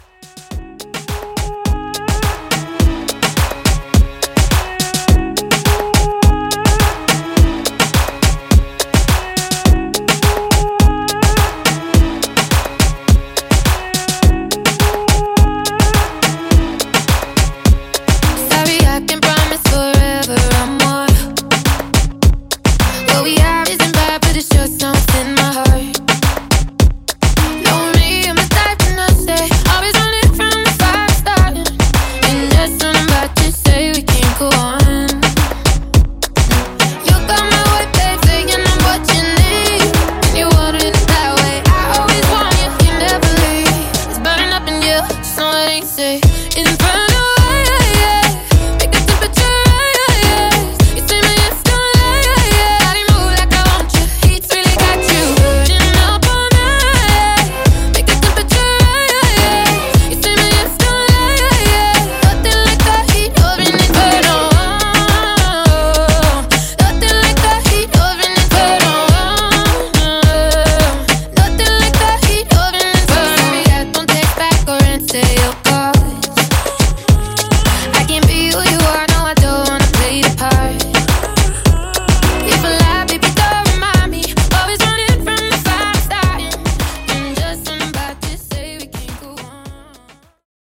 DANCE , DEEP HOUSE , MASHUPS